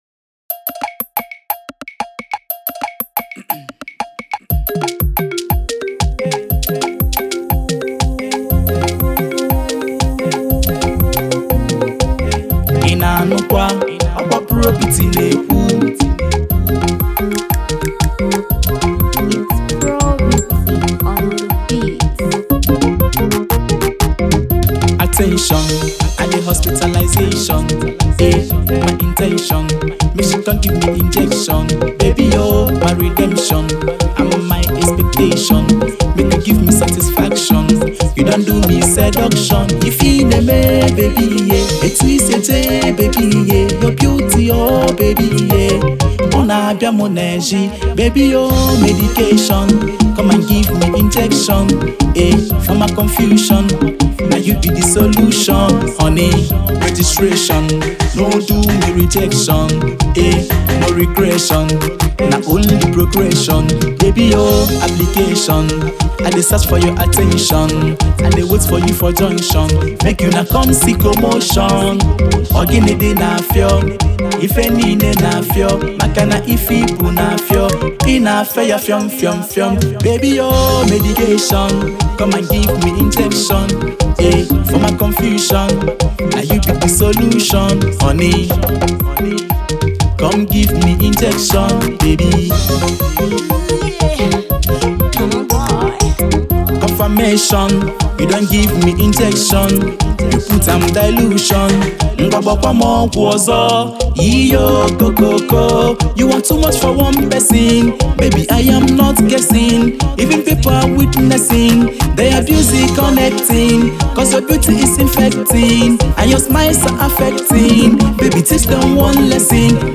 hip-hop and R&B